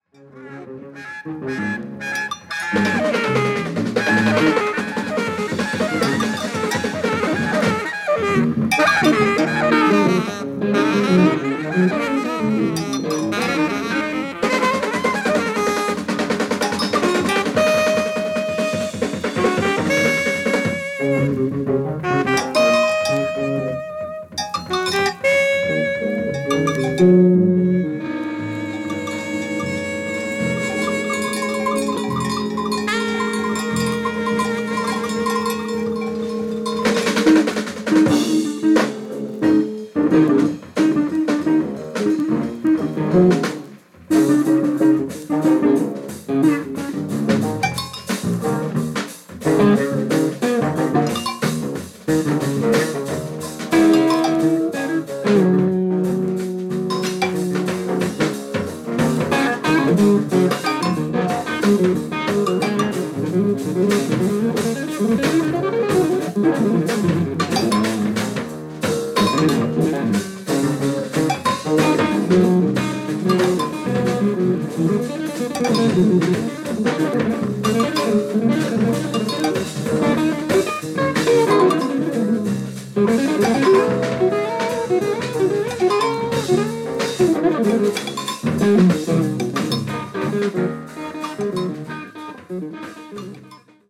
スタジオ録音・ライブ録音を合わせて２枚組
エレクトリック感、プログレ感、サイケ感を感じるお勧め盤！！！